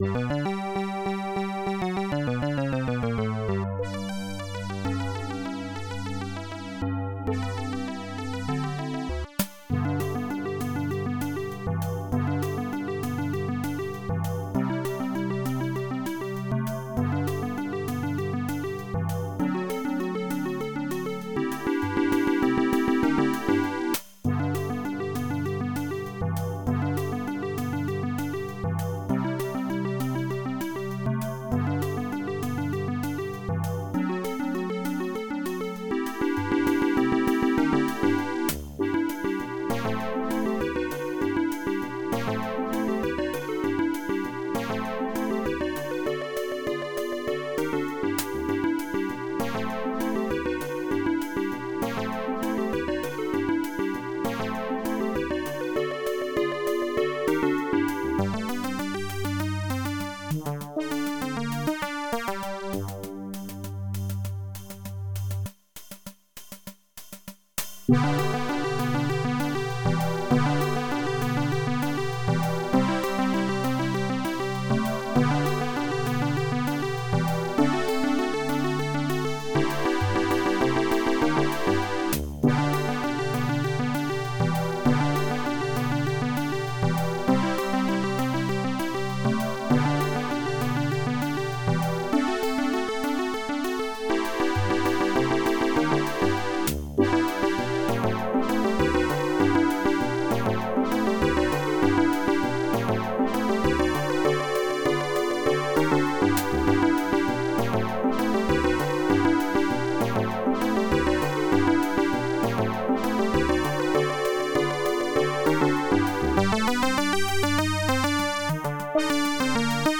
AdLib/Roland Song